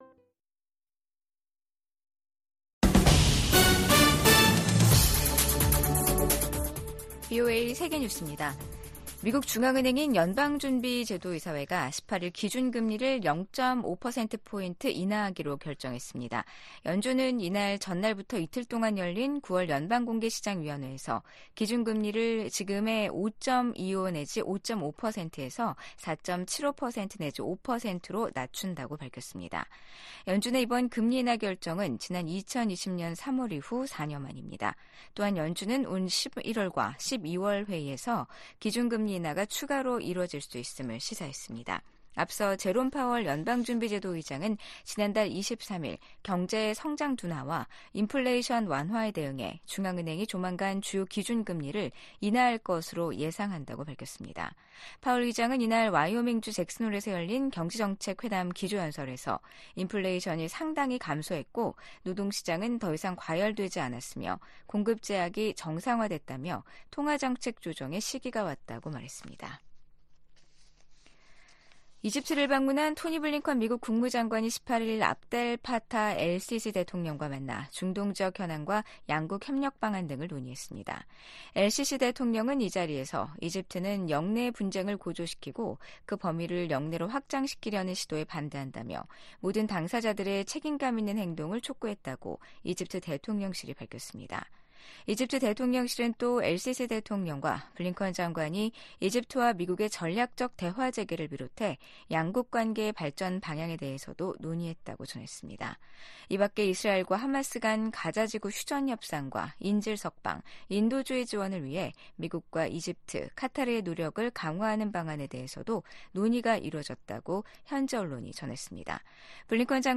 VOA 한국어 아침 뉴스 프로그램 '워싱턴 뉴스 광장' 2024년 9월 19일 방송입니다. 북한이 한반도 시각 18일 탄도미사일 여러 발을 발사했습니다. 미국은 이번 발사가 다수의 유엔 안보리 결의 위반이라며 규탄했습니다. 한미연합사령관 지명자가 북한의 핵과 미사일 역량 진전을 최대 도전 과제로 지목했습니다.